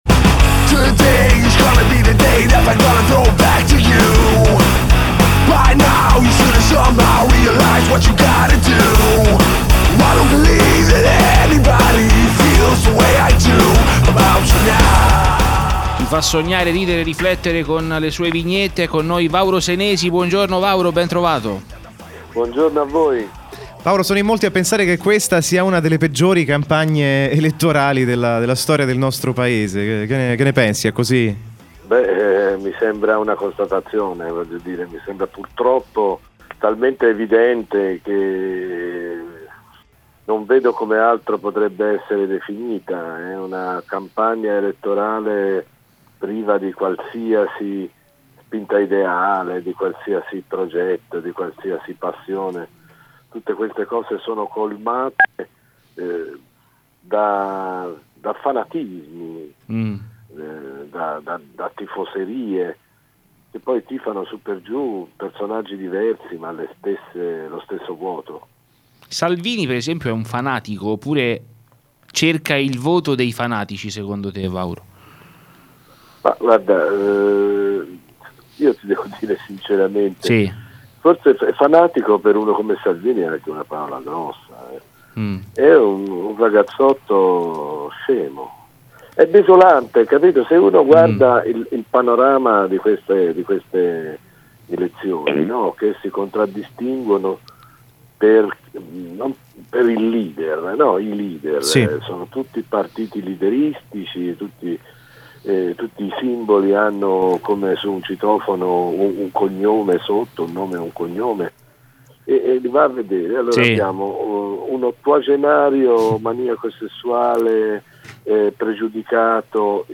“VAURO: BASTA LEADER” – Intervista integrale Radio Cusano Campus